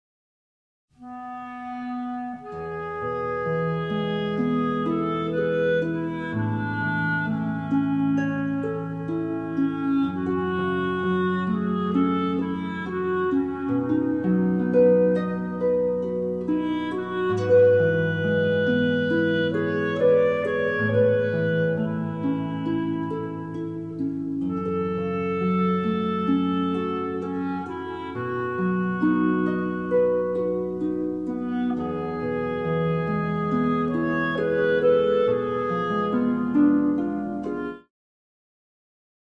for harp and clarinet